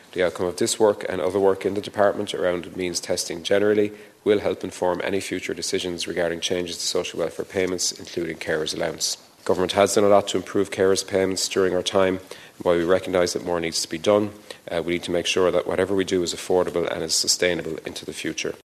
Junior Minister for Social Protection, Joe O’Brien, says they will take that group’s recommendations onboard, before deciding on the means test………..